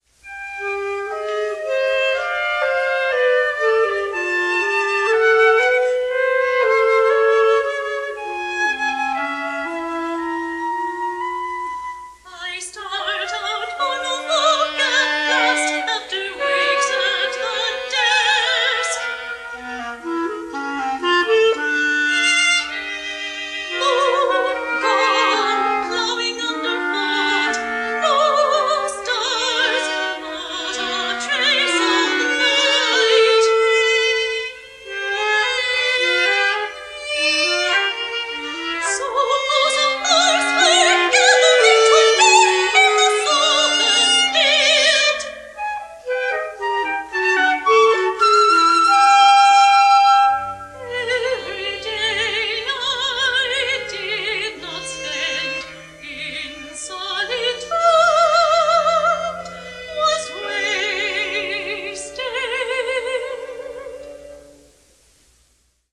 for Soprano with Ensemble or Piano
Soprano with Piano; or Flute or Oboe and Clarinet;
Ensemble
soprano
Archival Recordings